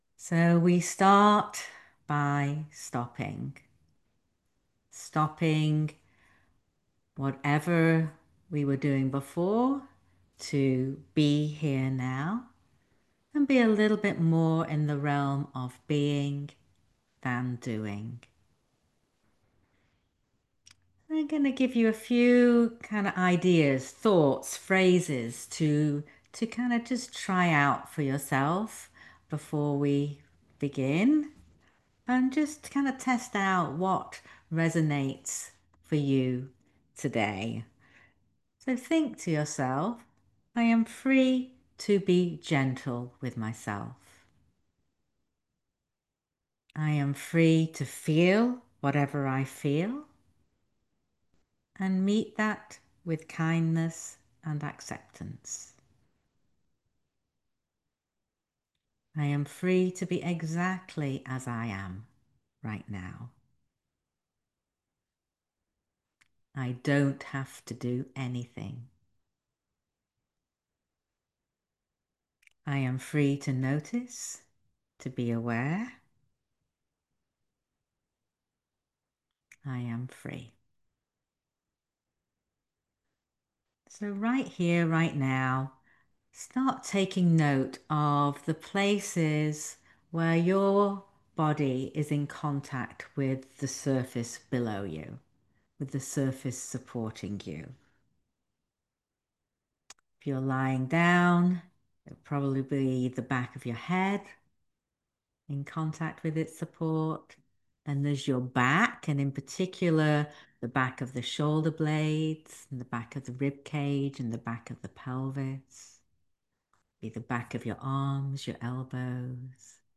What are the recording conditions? talk given during a live session on August 29, 2025. This talk includes a simple body scan, based on a practice from Yoga Nidra, which doesn’t ask anything of you except noting and naming of parts of the body.